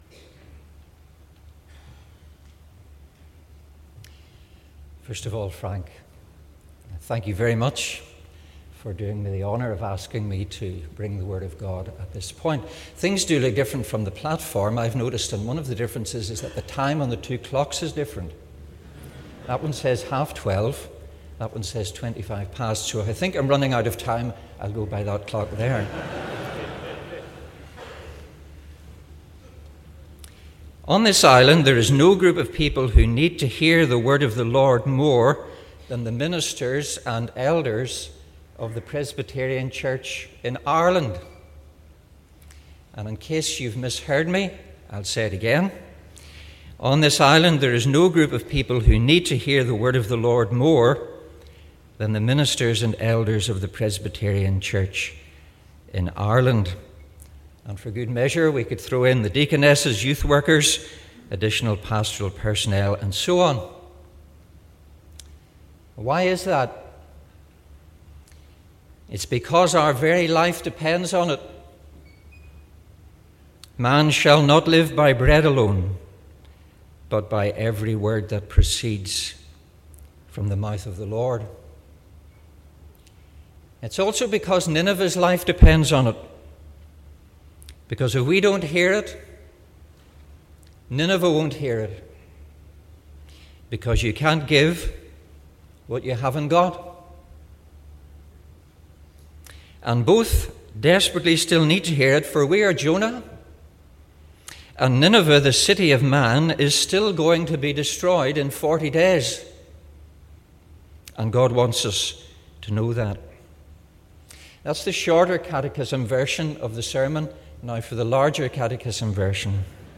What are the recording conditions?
GA2016 – Address at Wednesday Afternoon Worship The Assembly met in Assembly Buildings, Belfast from Monday, 6th June until Friday, 10th June, 2016.